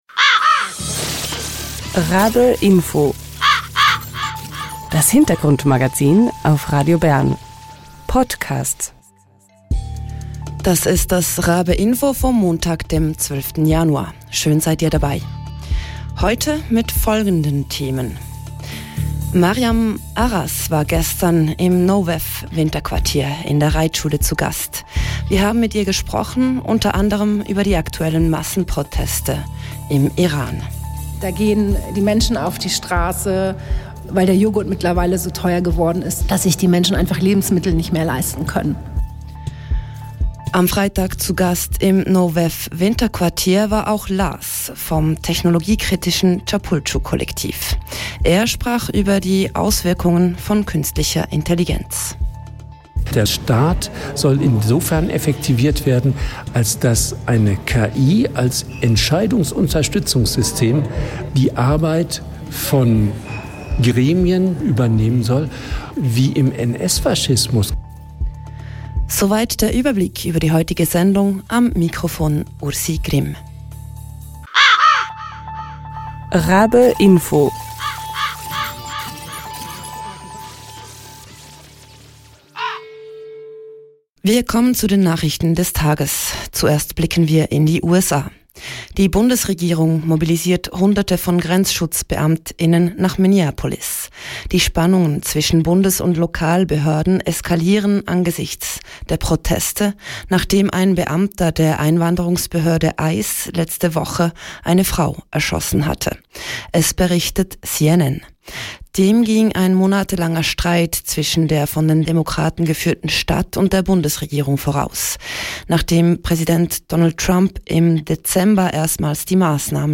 In der heutigen Infosendung berichten wir vom NO WEF Winterquartier 2026 in der Reitschule.